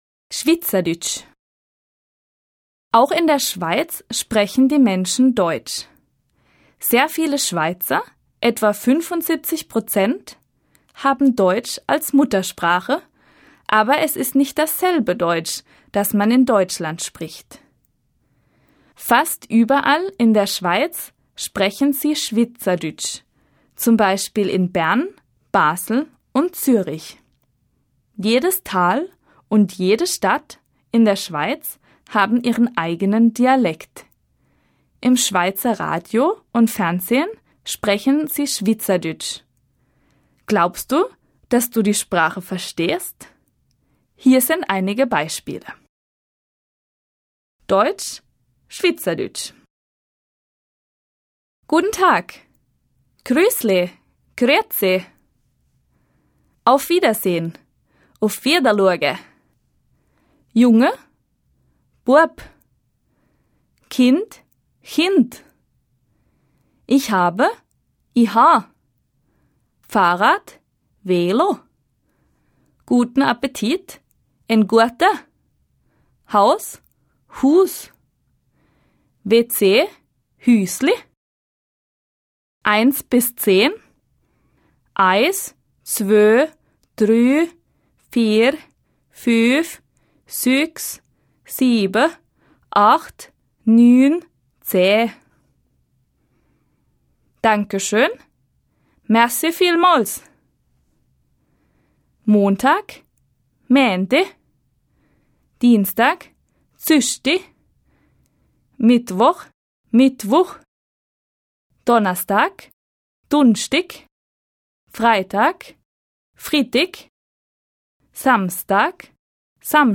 Schwyzerdütsch